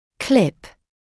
[klɪp][клип]клипса; защелка; клипса-замок (напр. безлигатурного брекета)